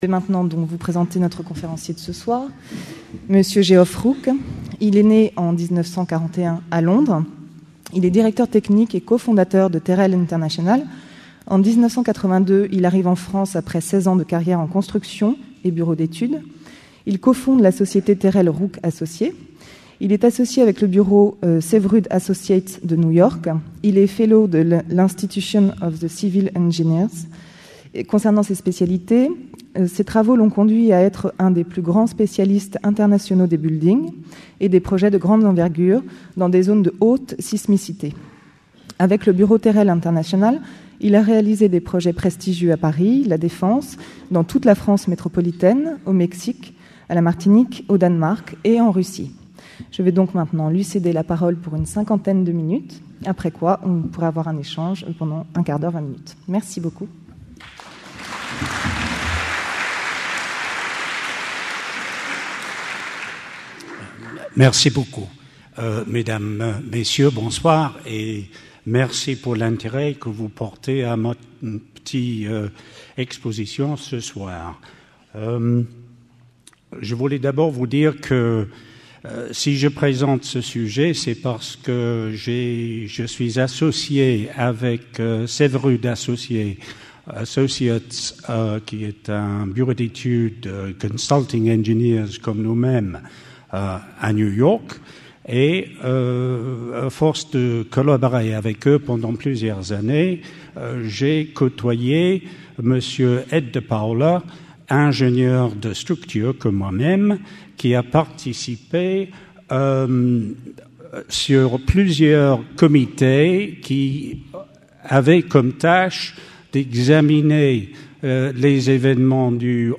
Cette conférence aura pour objet de passer en revue la nature des bâtiments concernés, leur réponses initiales à l'agression ainsi que les explications des différents mécanismes de l'effondrement final. Les questions soulevées pour la prochaine génération de notre réglementation de la construction concernent, entre autres, la stabilité au feu, la robustesse des constructions, les sorties de secours, etc...